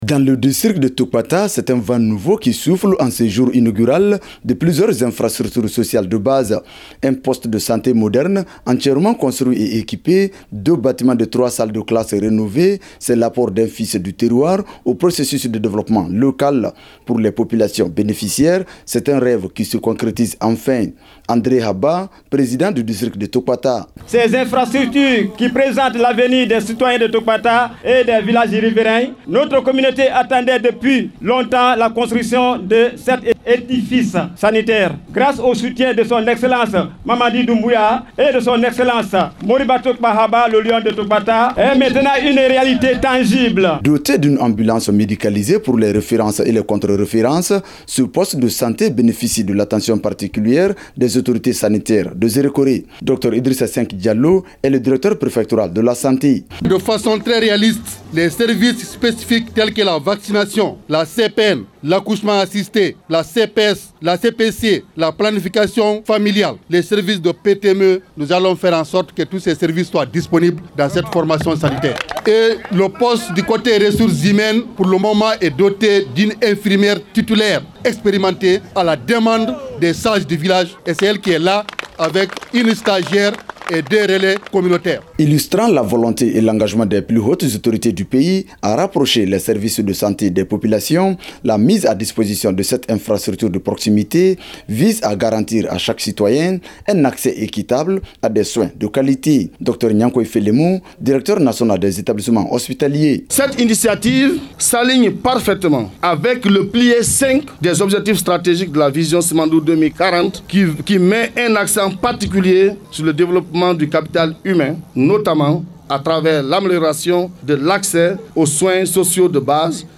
Le reportage audio